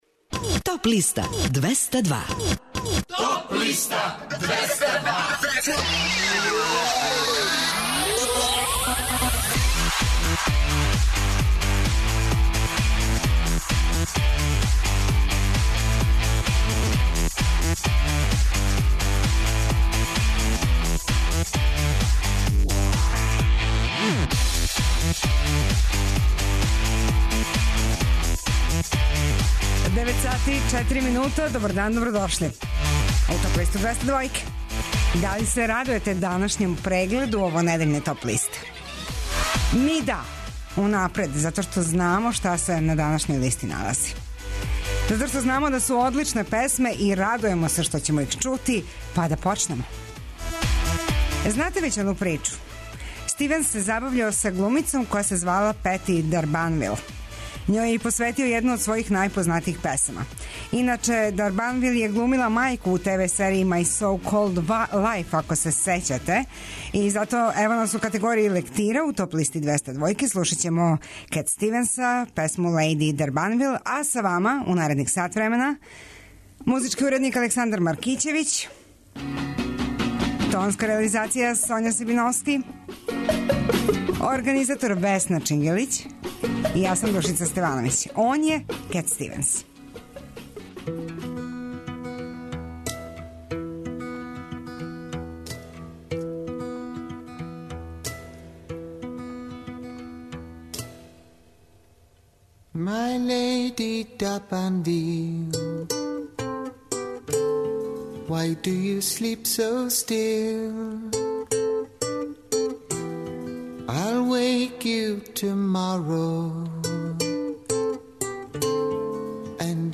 преузми : 23.89 MB Топ листа Autor: Београд 202 Емисија садржи више различитих жанровских подлиста.